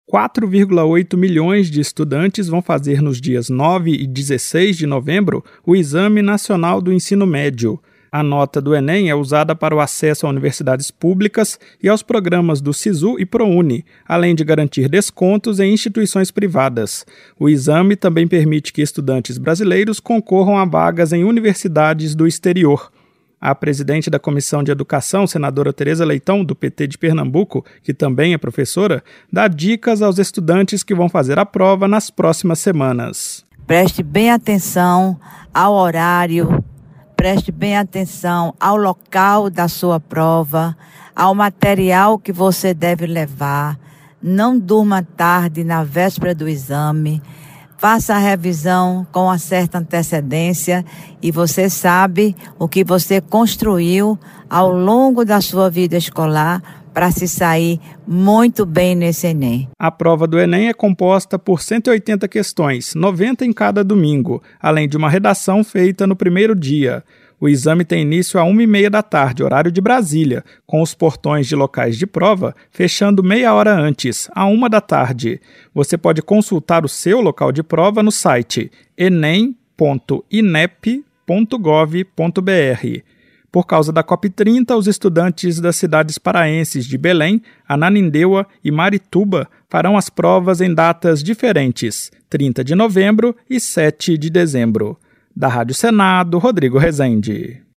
Cerca de 4,8 milhões de estudantes farão o Exame Nacional do Ensino Médio (Enem) nos dias 9 e 16 de novembro. A nota da prova permite acesso a programas como Sisu e Prouni e a vagas em universidades no Brasil e no exterior. A presidente da Comissão de Educação, senadora Teresa Leitão (PT-PE), orienta os candidatos a verificarem o seu local de prova e a se atentarem aos horários e materiais exigidos no dia do exame.